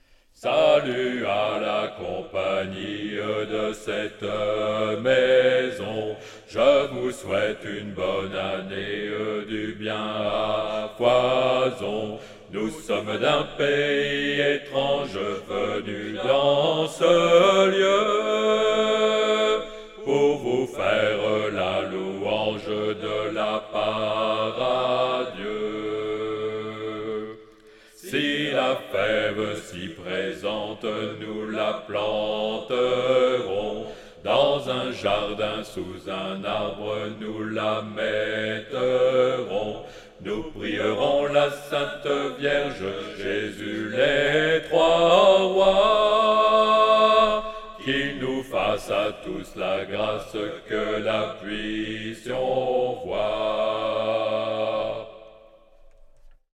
traditionnel
Basse
à 4 voix